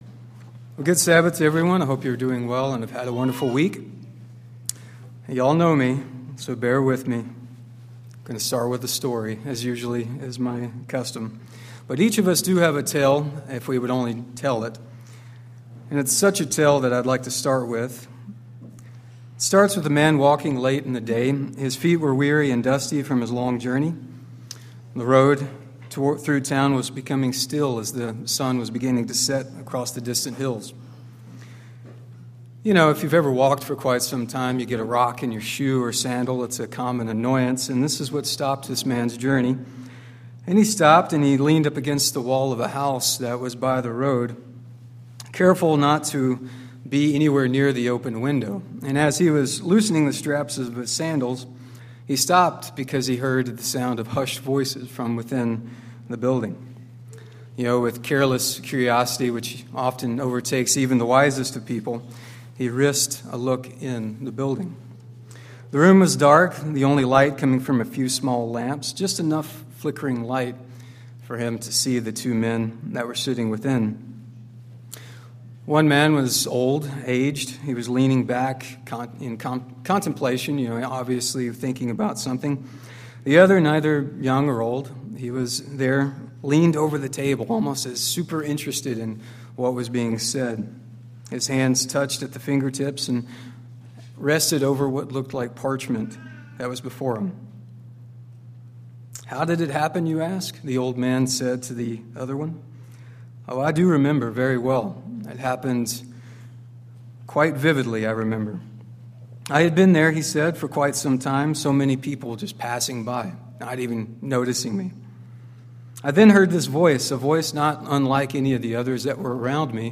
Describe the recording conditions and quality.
Given in Kennewick, WA Chewelah, WA Spokane, WA